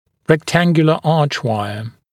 [rek’tæŋgjələ ‘ɑːʧˌwaɪə][рэк’тэнгйэлэ ‘а:чˌуайэ]дуга прямоугольного сечения, прямоугольная дуга, граненая дуга